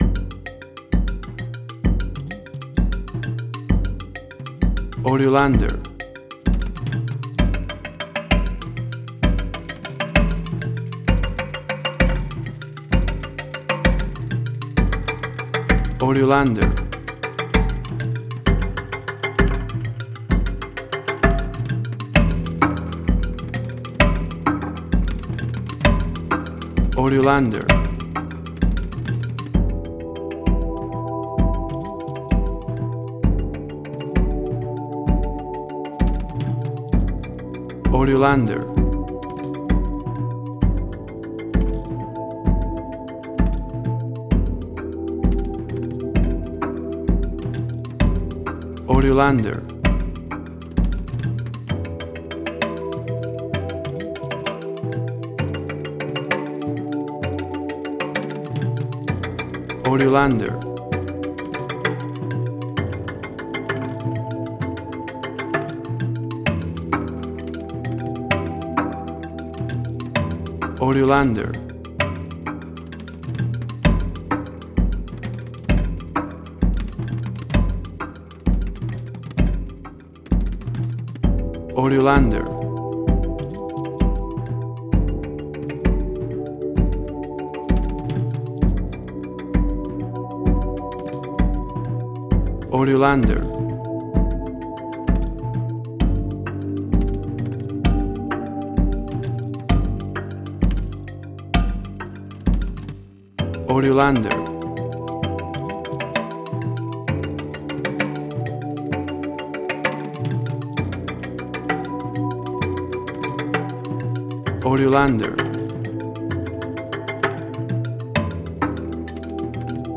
World Fusion_Similar_BBC Documentaries.
WAV Sample Rate: 16-Bit stereo, 44.1 kHz
Tempo (BPM): 69